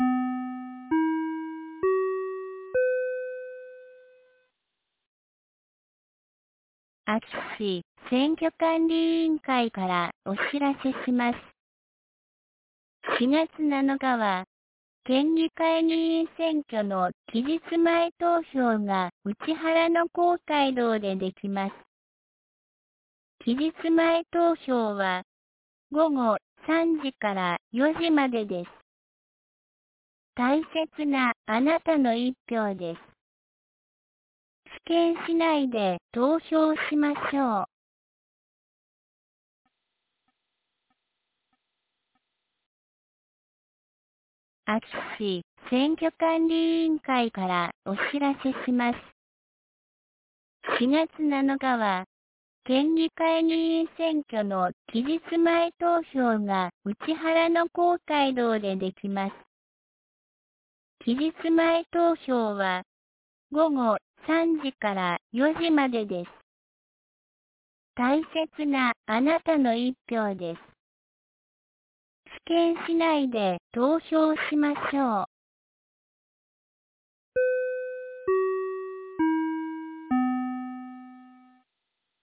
2023年04月04日 12時11分に、安芸市より井ノ口へ放送がありました。